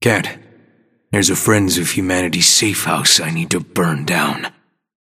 Grey Talon voice line - Can't. There's a Friends of Humanity safehouse I need to burn down.